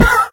horse
hit1.ogg